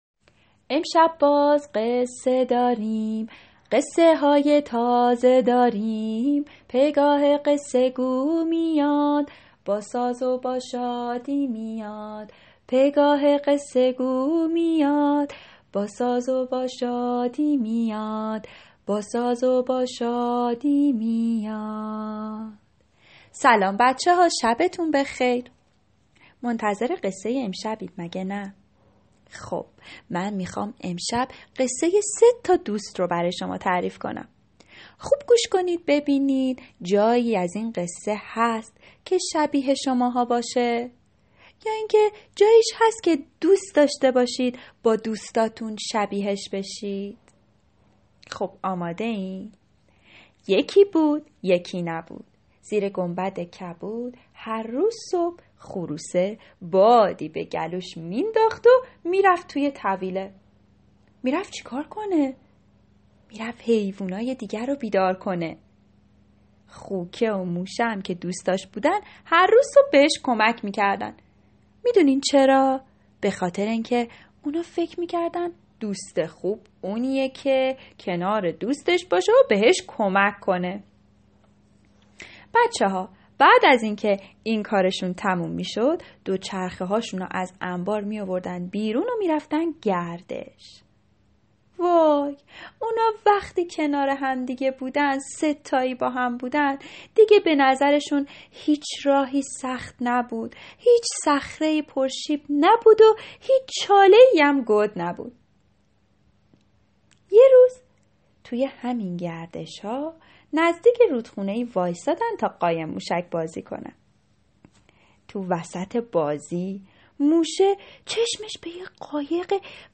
قصه صوتی کودکانه دوستان